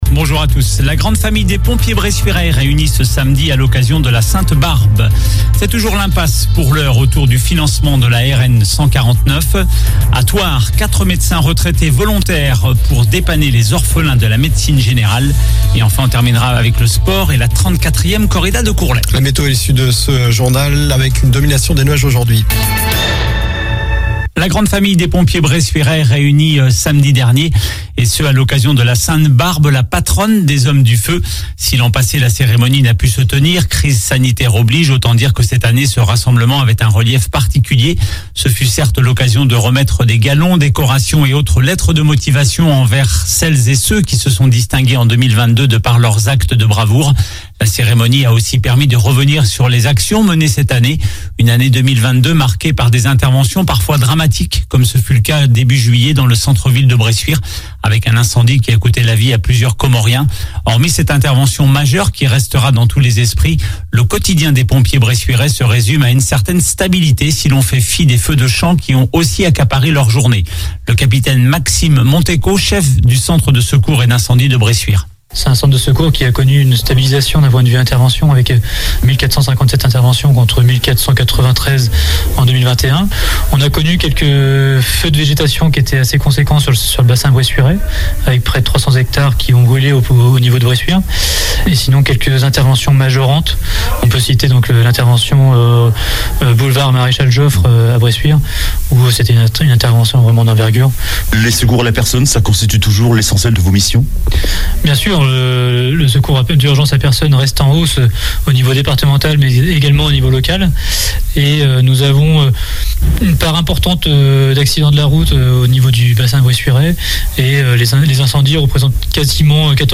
Journal du lundi 19 décembre